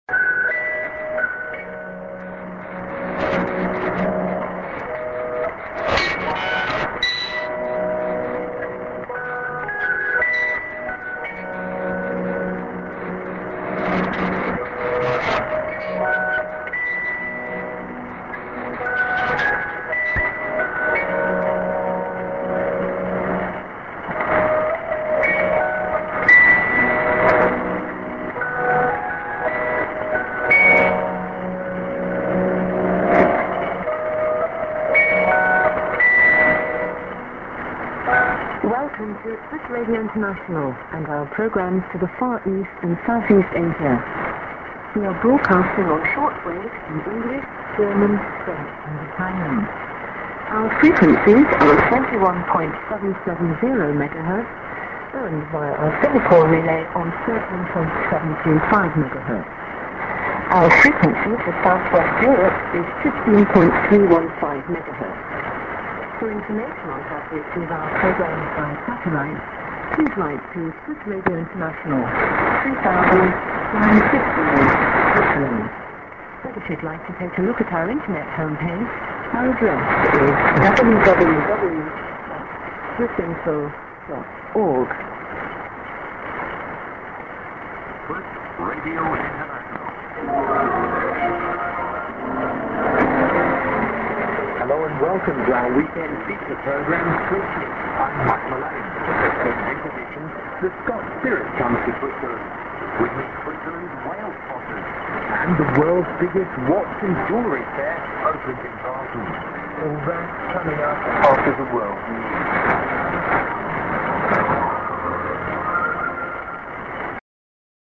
ST. IS->ID+00'40":SKJ(women)->